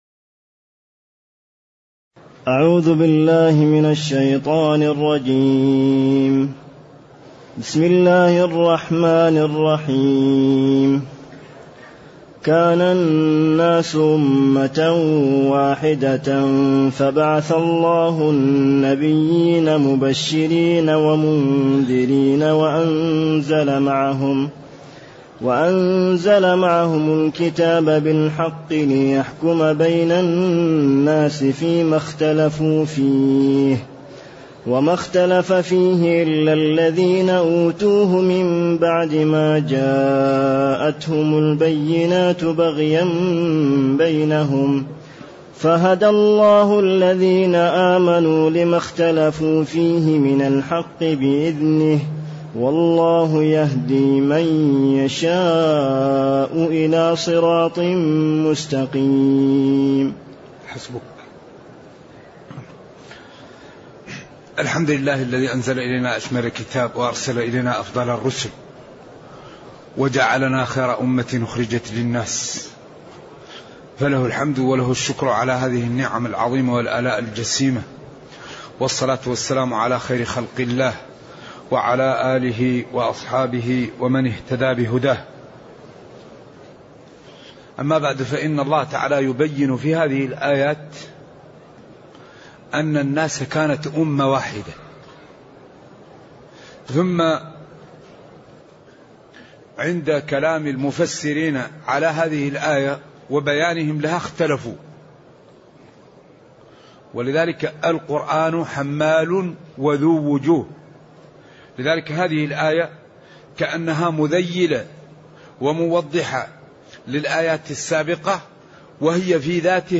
تاريخ النشر ٢٧ شعبان ١٤٢٨ هـ المكان: المسجد النبوي الشيخ